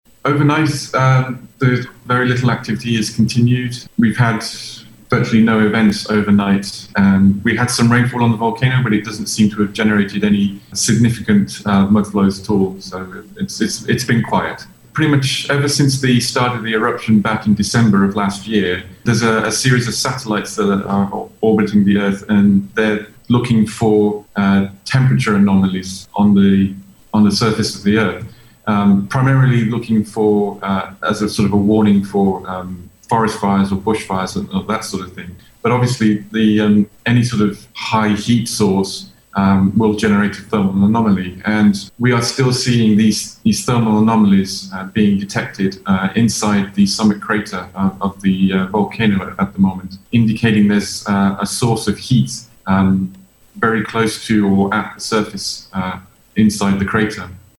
providing an update on the activity at the La Soufriere volcano during NBC radio’s Eying La Soufriere program this morning